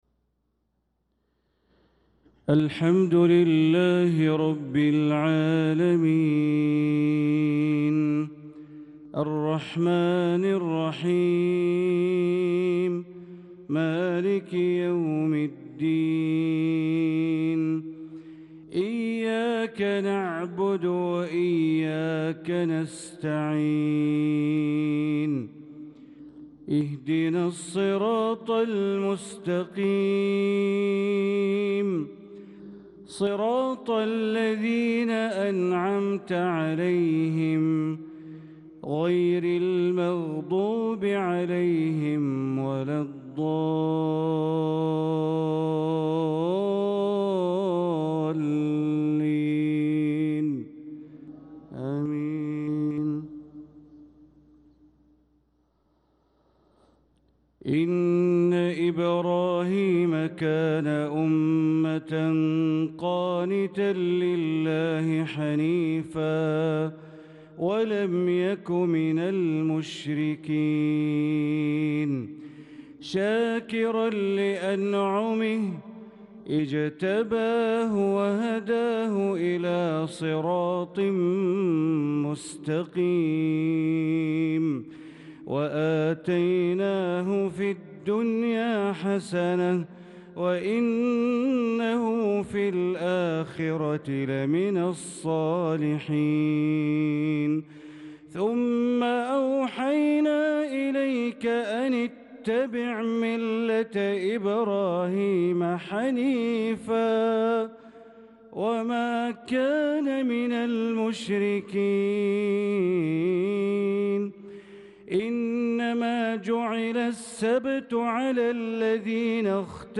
صلاة العشاء للقارئ بندر بليلة 19 ذو القعدة 1445 هـ
تِلَاوَات الْحَرَمَيْن .